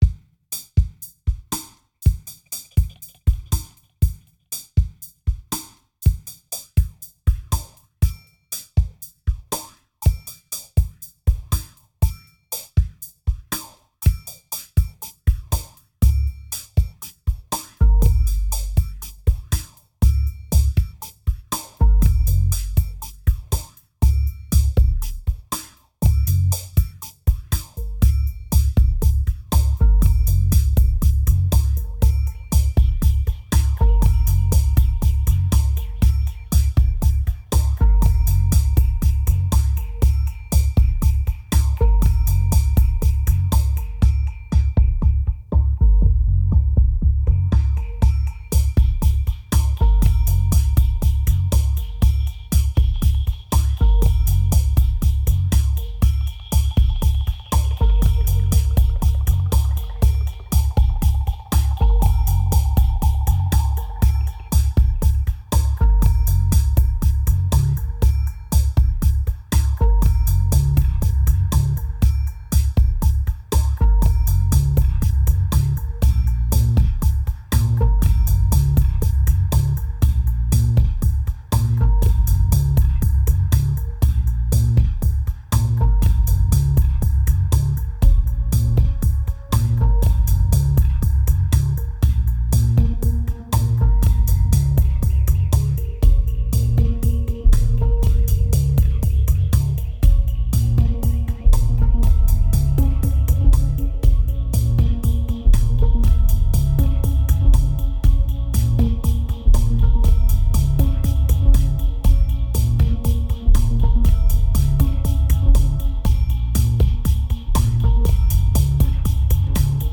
1813📈 - -18%🤔 - 120BPM🔊 - 2010-04-11📅 - -345🌟